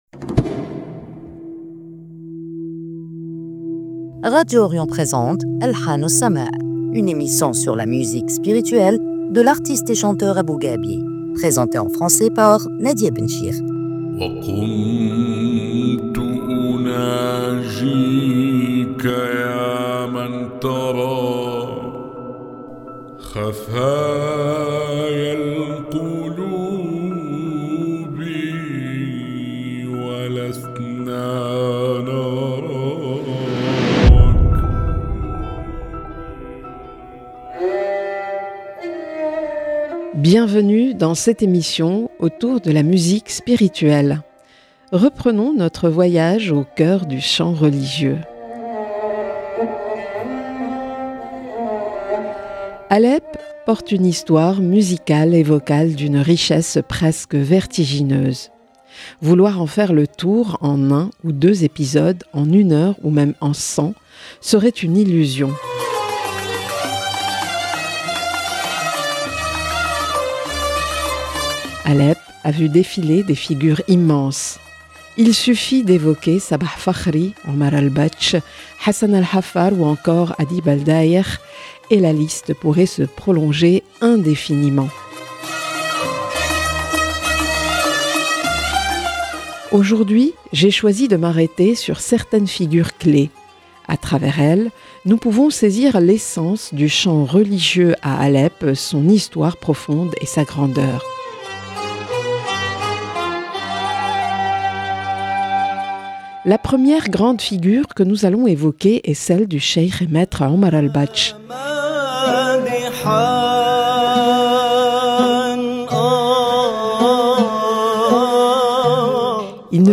une émission sur la musique spirituelle